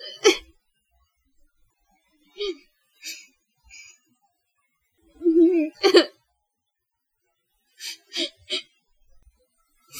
violence_context / data /crying /crying49.wav
crying49.wav